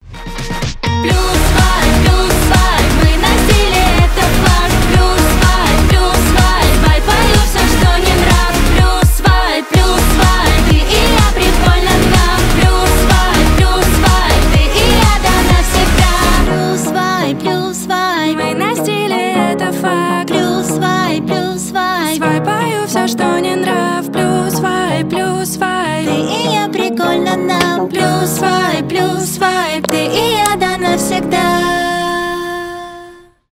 позитивные , поп